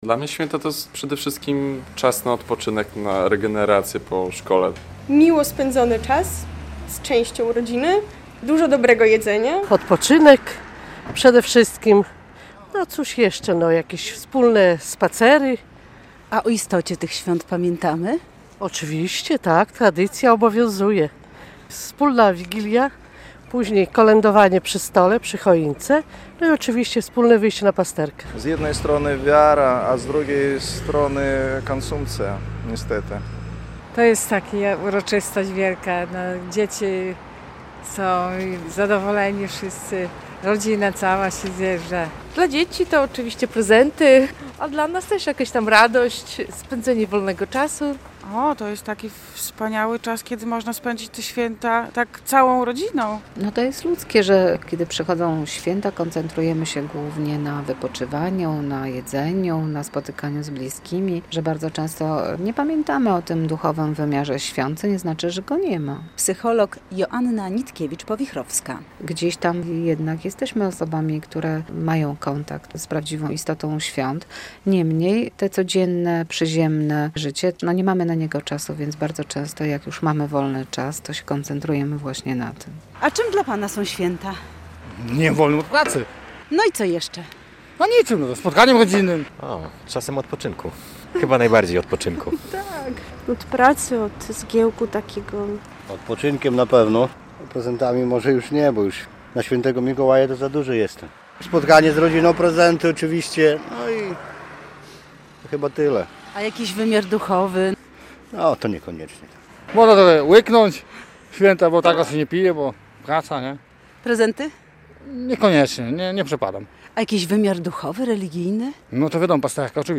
relacja
Spytaliśmy białostoczan, czym są dla nich święta.